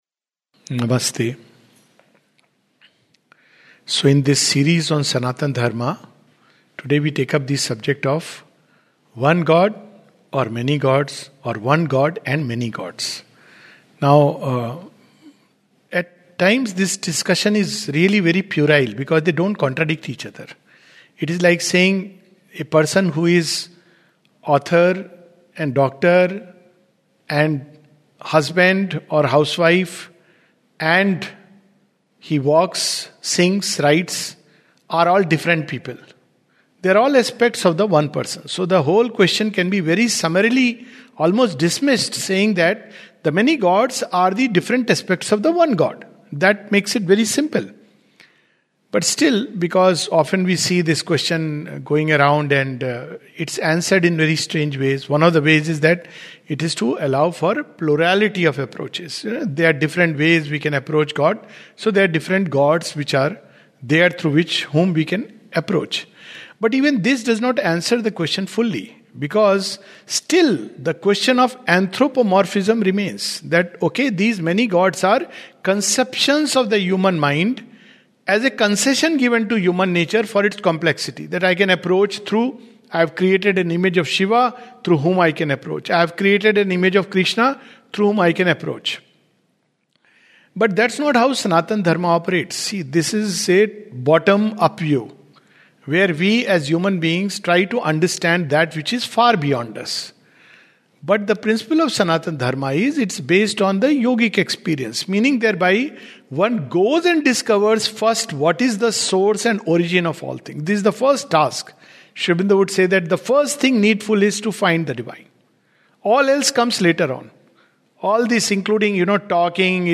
This talk